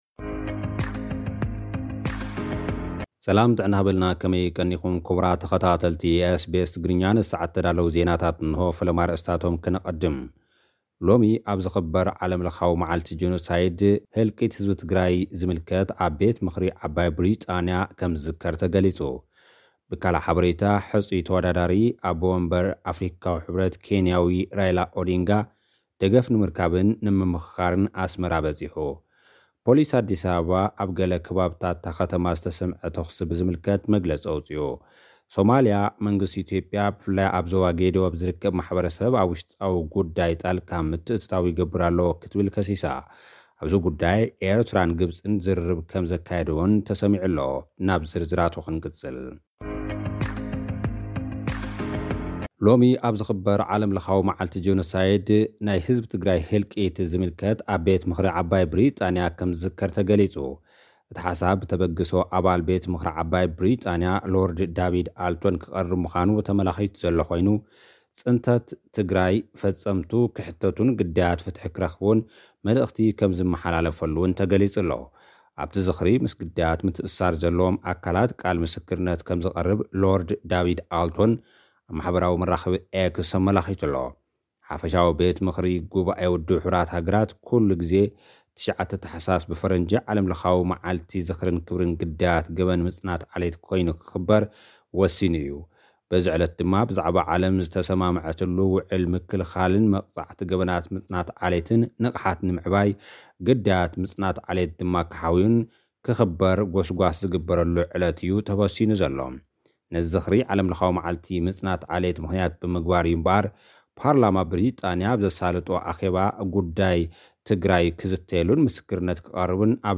ልኡኽና ዝሰደደልና ጸብጻብ፡